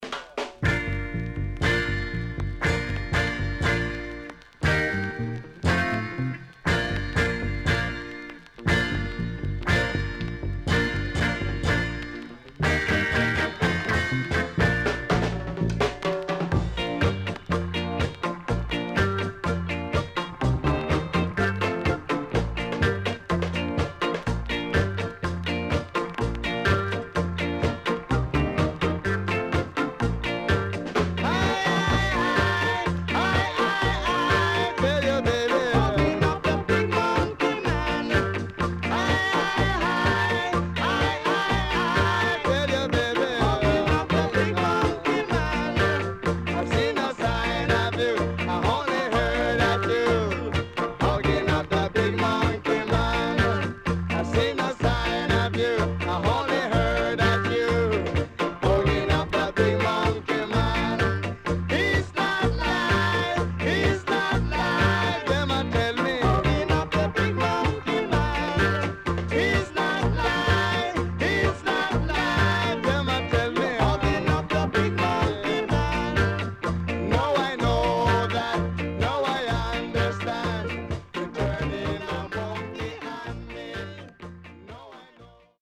HOME > REGGAE / ROOTS  >  EARLY REGGAE
CONDITION SIDE A:VG(OK)
SIDE A:所々チリノイズがあり、少しプチノイズ入ります。